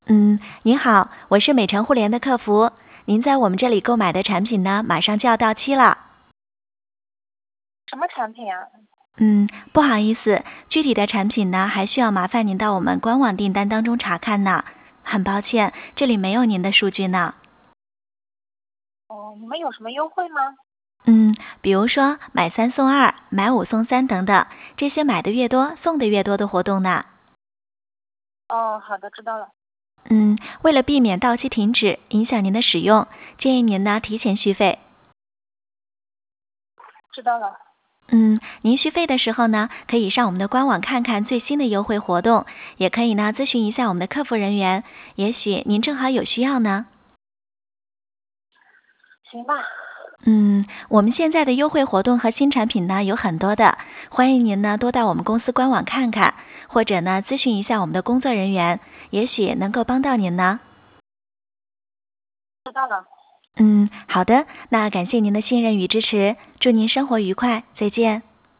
通过专业级真人式模拟销售或客服专家，和客户进行多轮互动高效沟通交流，快速完成ABC类意向客户的筛选和分类